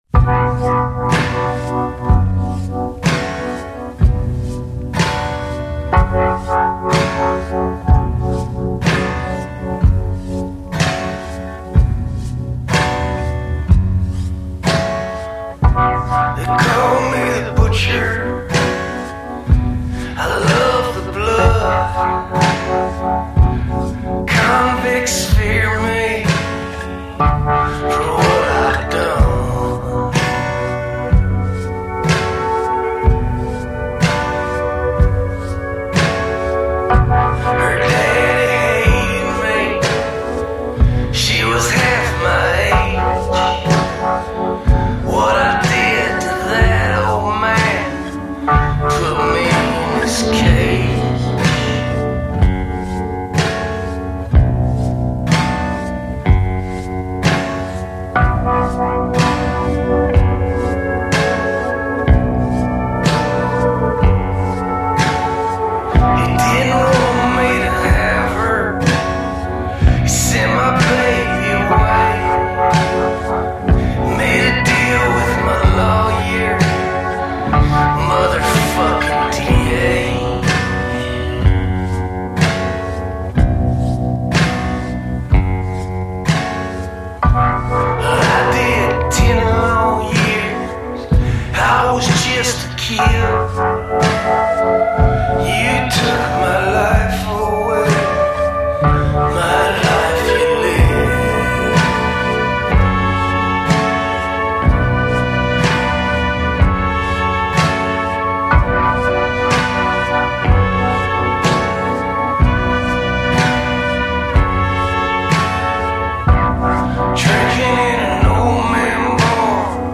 We need sad songs.